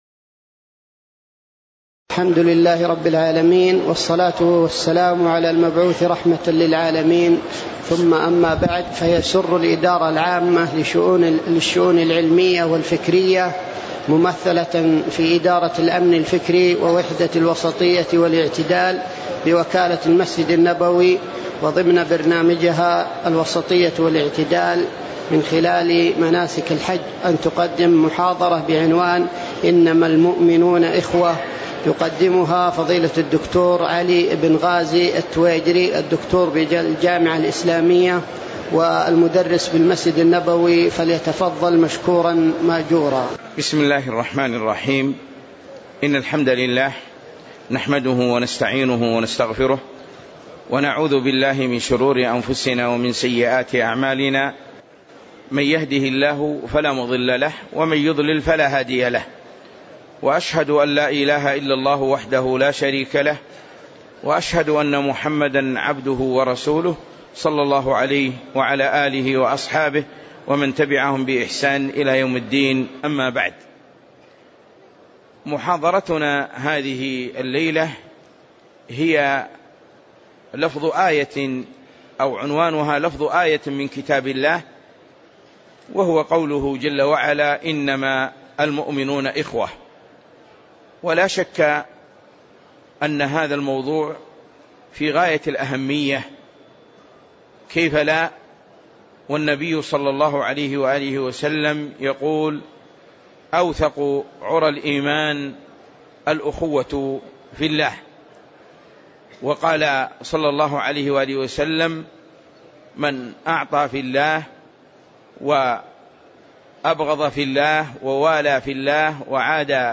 محاضرة - إنما المؤمنون إخوة